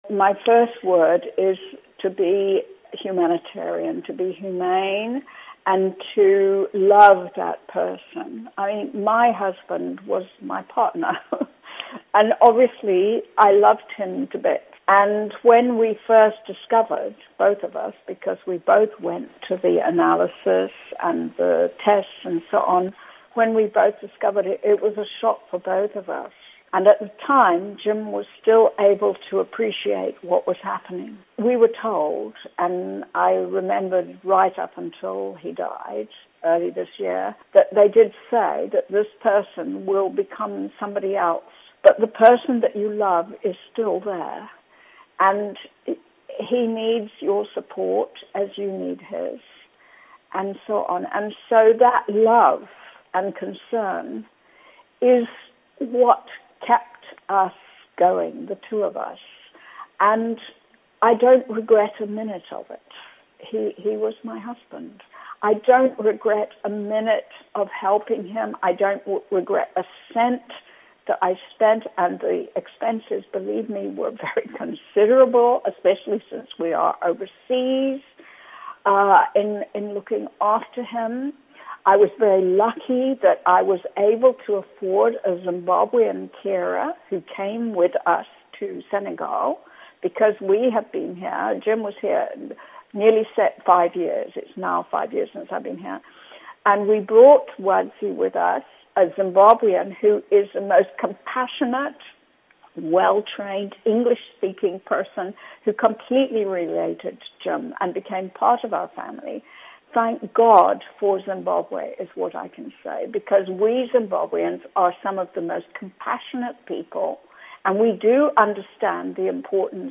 Interview With Ambassador Trudy Stevenson